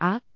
speech
syllable
pronunciation
aak2.wav